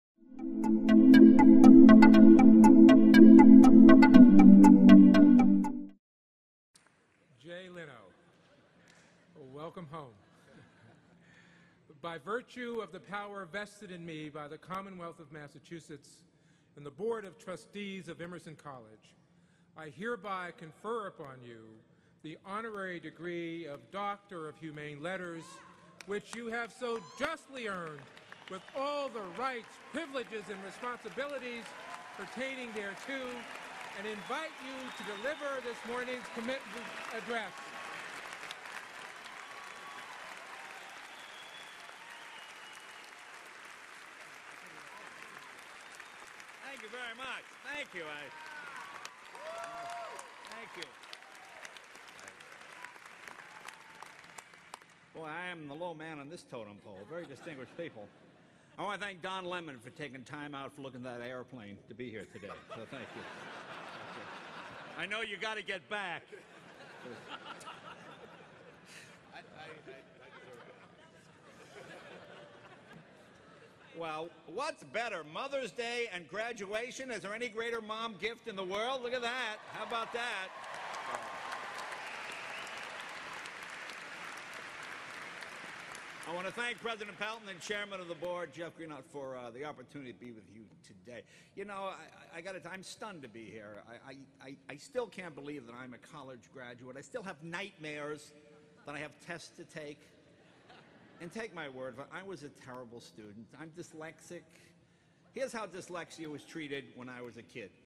公众人物毕业演讲 第203期:杰雷诺2014爱默生学院(1) 听力文件下载—在线英语听力室